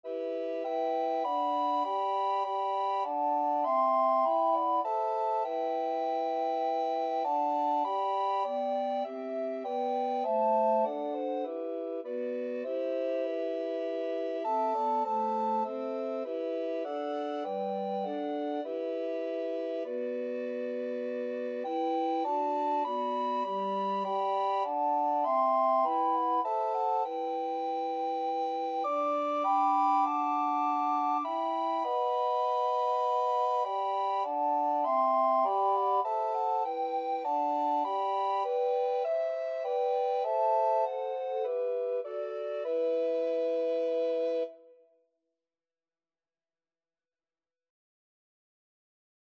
Christmas Christmas Recorder Quartet Sheet Music O Come, O Come, Emmanuel
Free Sheet music for Recorder Quartet
Soprano RecorderAlto RecorderTenor RecorderBass Recorder
E minor (Sounding Pitch) (View more E minor Music for Recorder Quartet )
4/4 (View more 4/4 Music)
Traditional (View more Traditional Recorder Quartet Music)